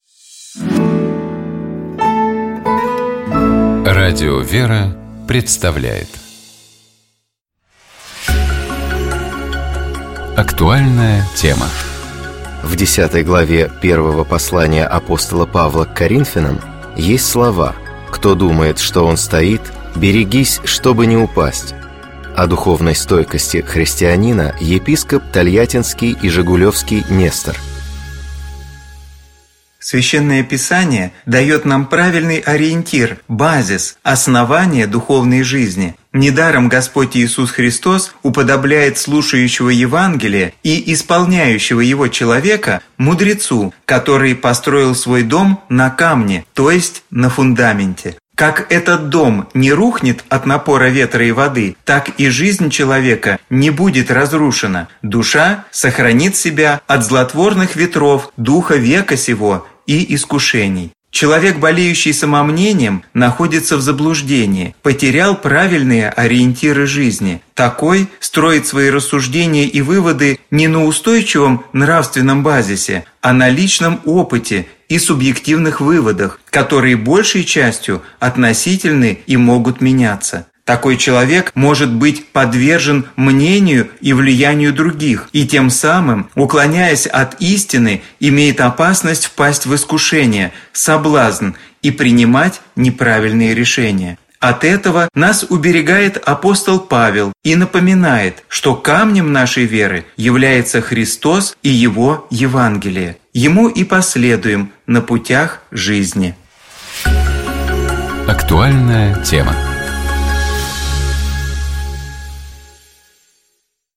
О духовной стойкости христианина, — епископ Тольяттинский и Жигулёвский Нестор.